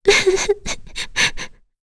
Kirze-Vox_Sad.wav